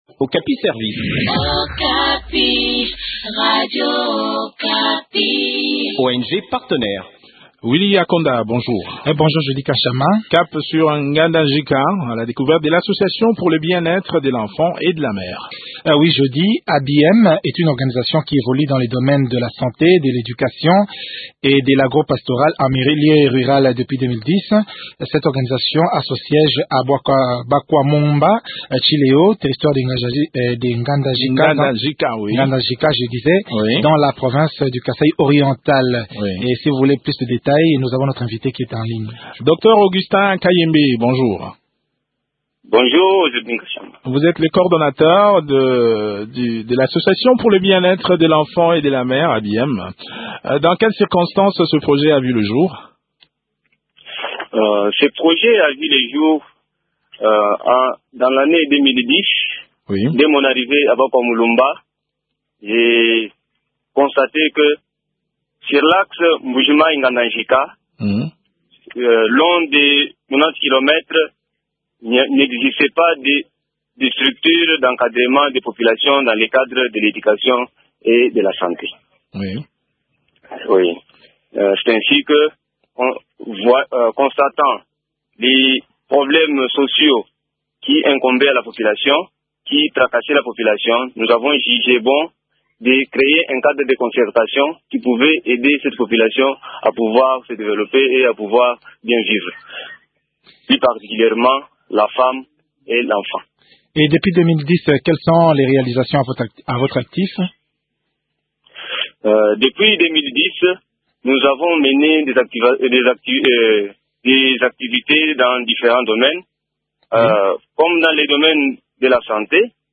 fait le point de leurs activités au micro